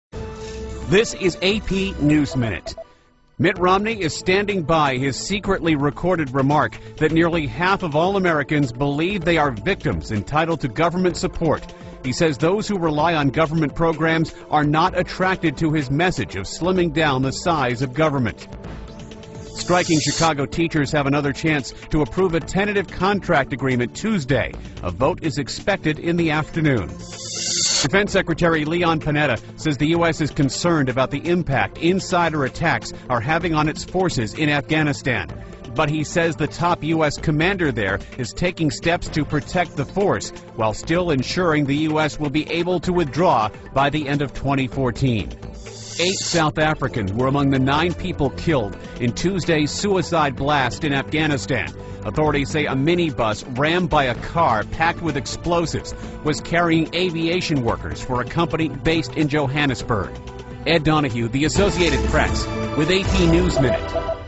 在线英语听力室美联社新闻一分钟 AP 2012-09-21的听力文件下载,美联社新闻一分钟2012,英语听力,英语新闻,英语MP3 由美联社编辑的一分钟国际电视新闻，报道每天发生的重大国际事件。电视新闻片长一分钟，一般包括五个小段，简明扼要，语言规范，便于大家快速了解世界大事。